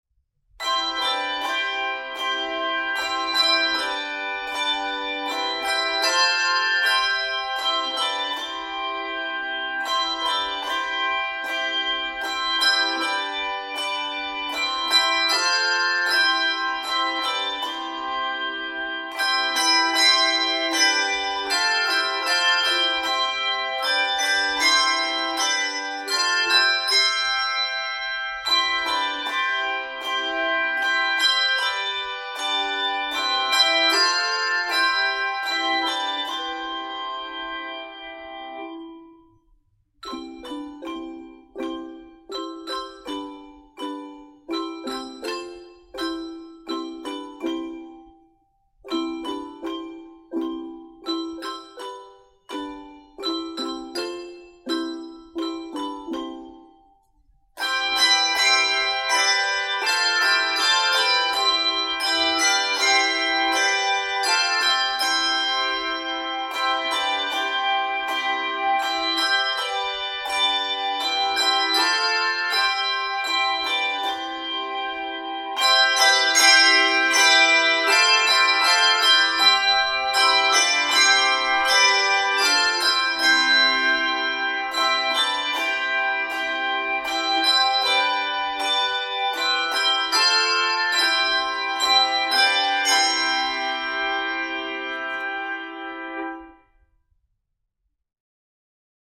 3/2 meter
in the key of G Major
is in the key of C Major